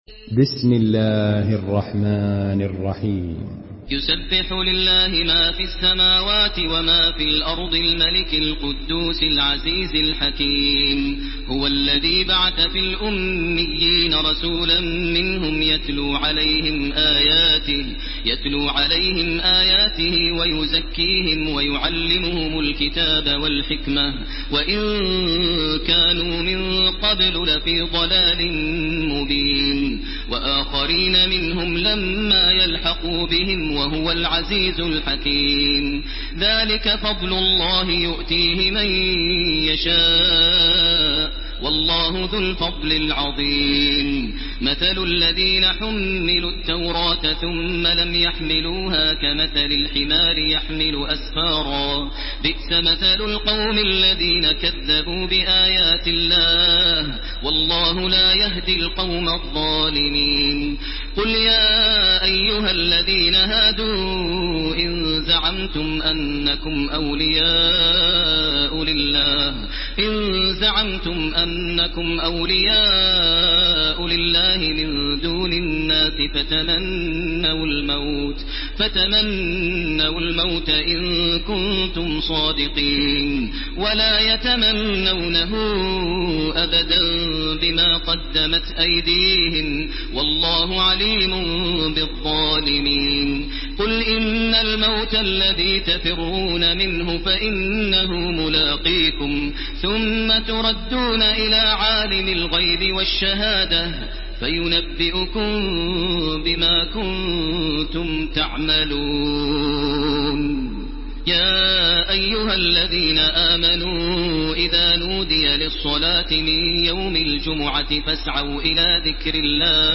Surah আল-জুমু‘আ MP3 in the Voice of Makkah Taraweeh 1430 in Hafs Narration
Murattal